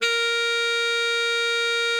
bari_sax_070.wav